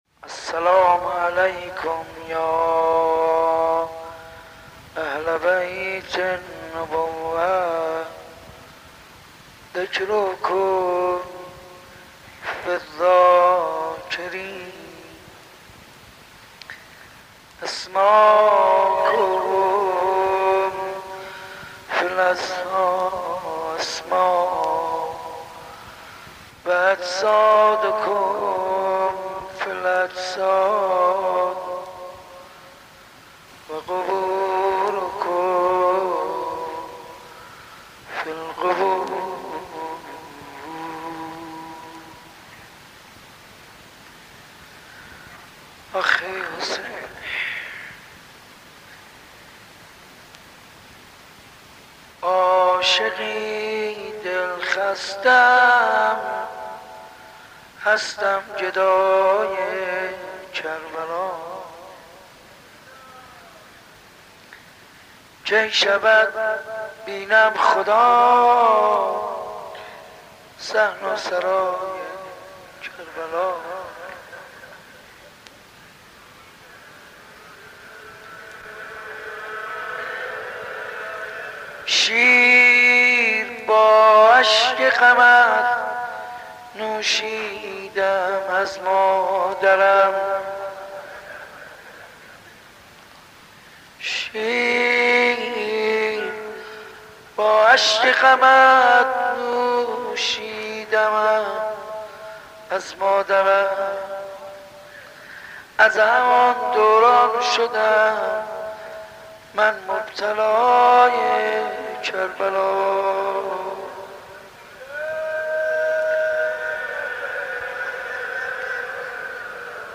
مناسبت : شب چهارم محرم
مداح : حاج منصور ارضی قالب : واحد